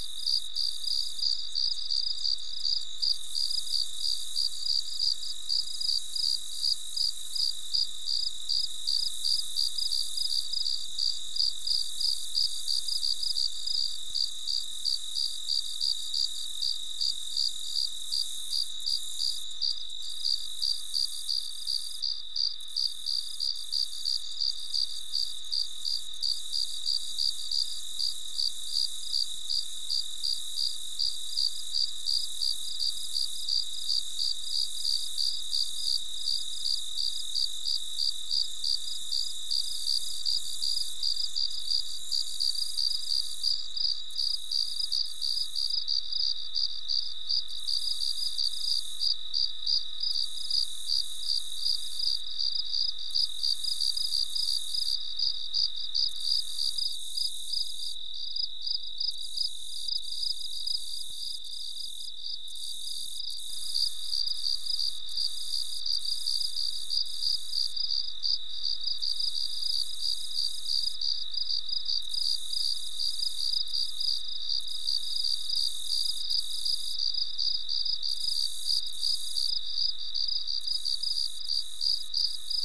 meadow ambience
Category 🐾 Animals